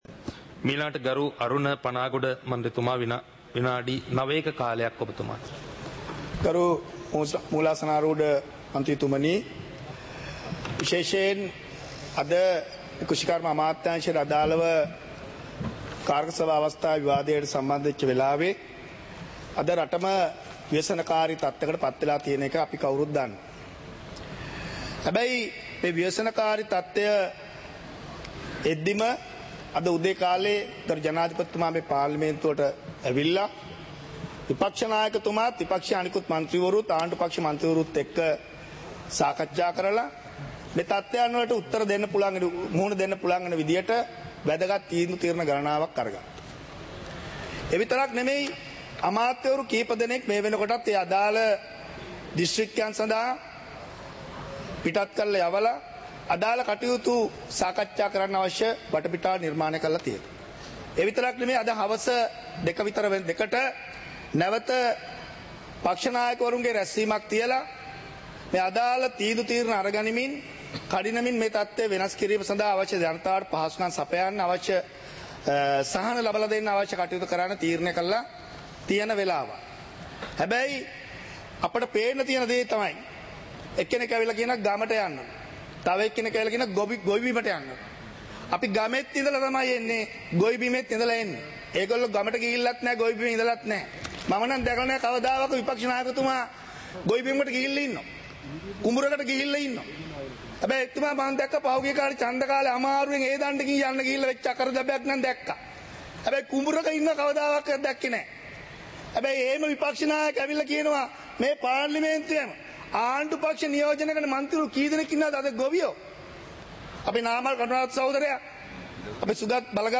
සභාවේ වැඩ කටයුතු (2025-11-27)